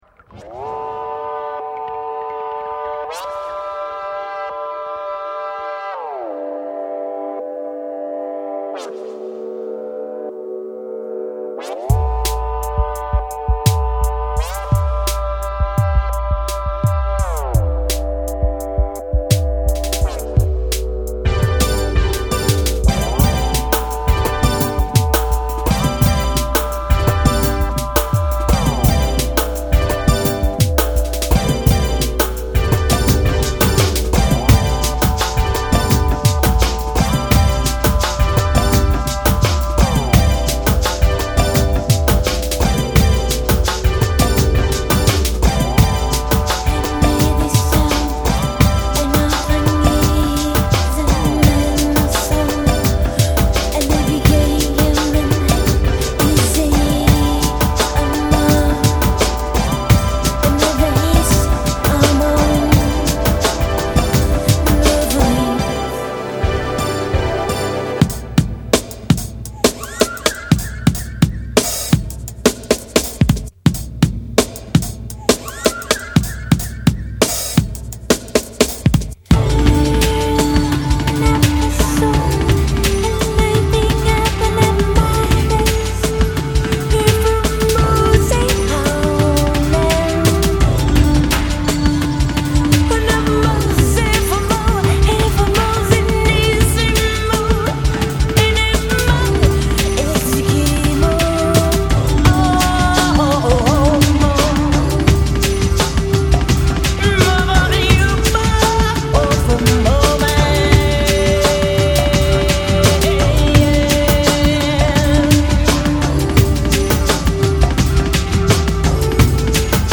electronic group